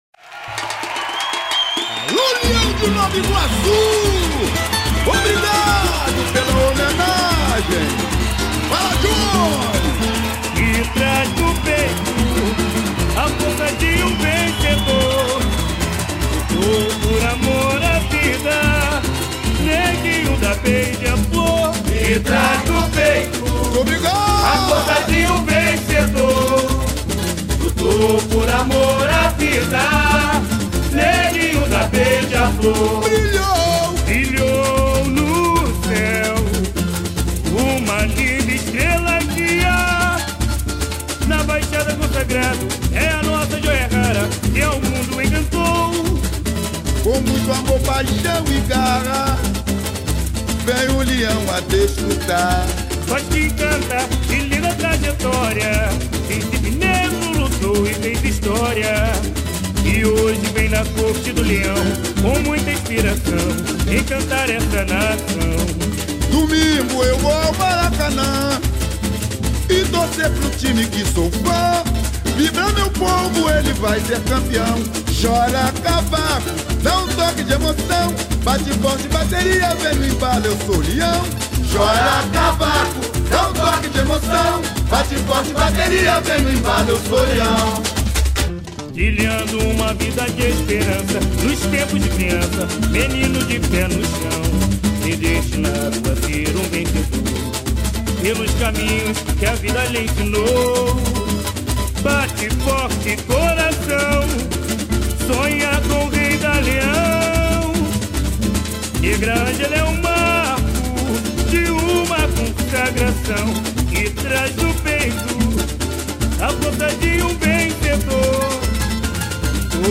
O samba-enredo